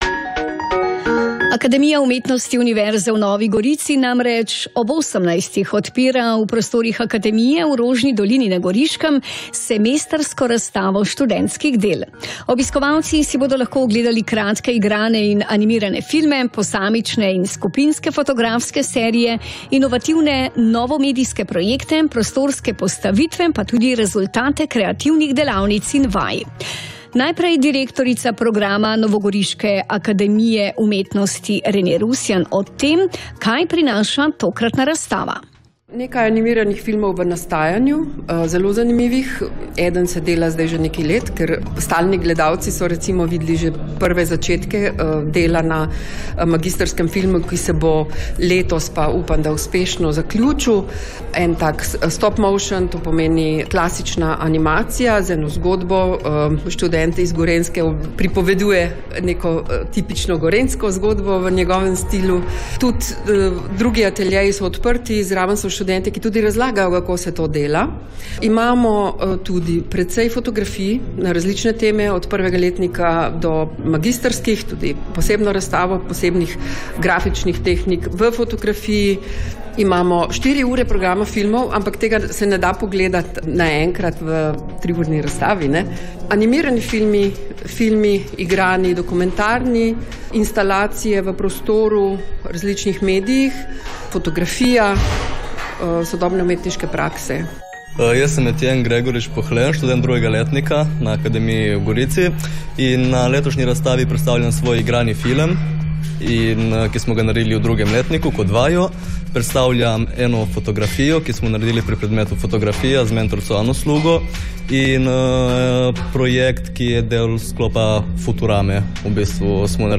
Radio Koper, Popoldanski program, Razstava študentskih del, 22.1.2026, radijski prispevek: